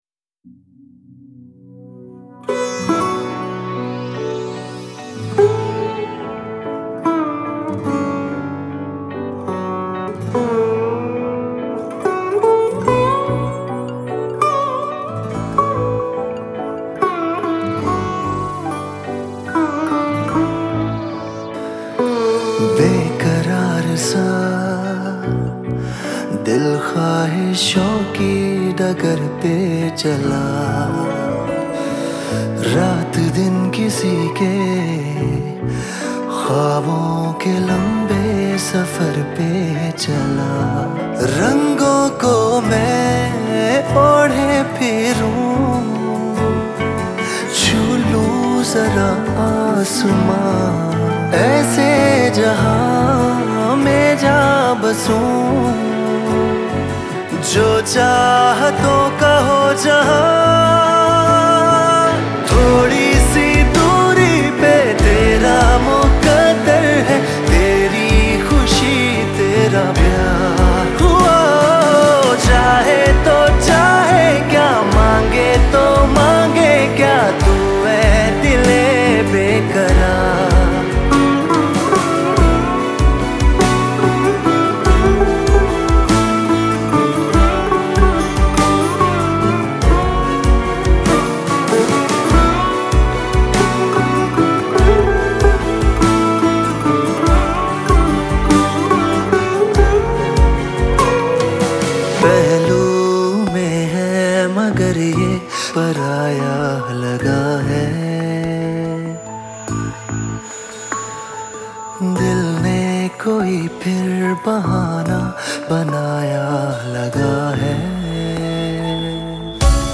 Sitar